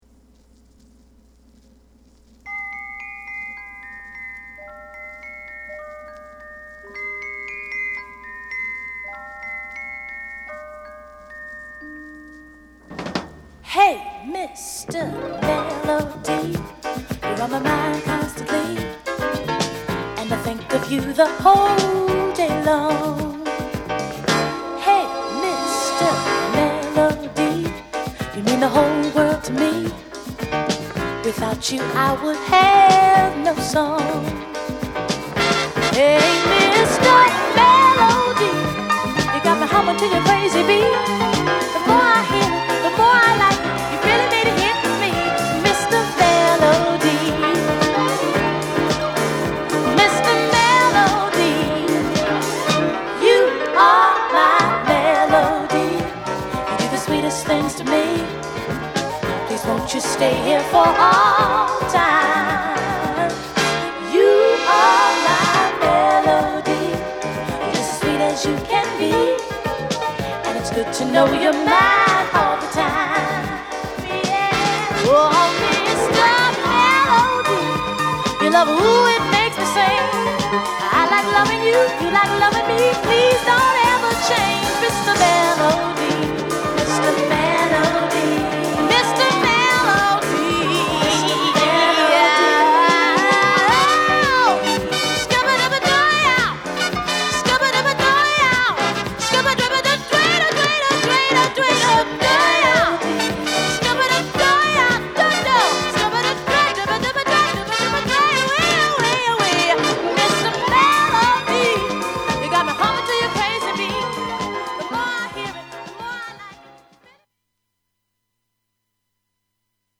Genre: Soul / R&B
ゆったりとした重みのある出だしから、